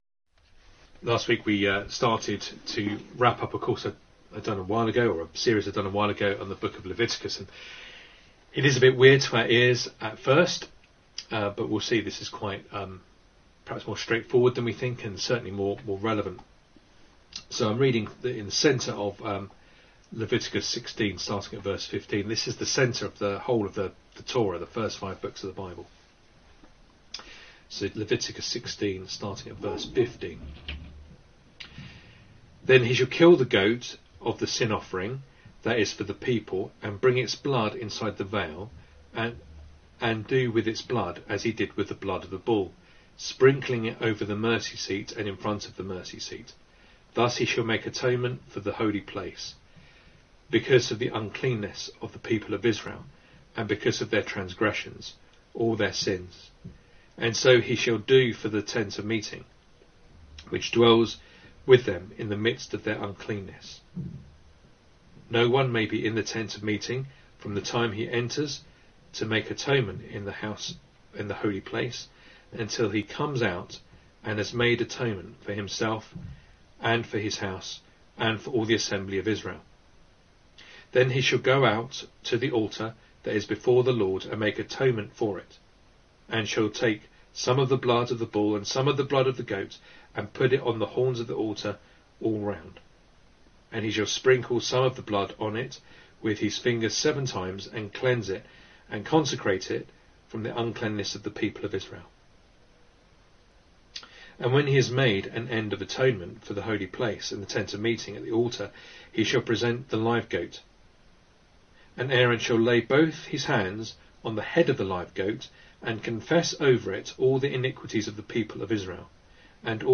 Leviticus Passage: Leviticus 16:15-22, John 1:35-38 Service Type: Sunday Morning Reading and Sermon Audio